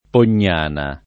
[ pon’n’ # na ]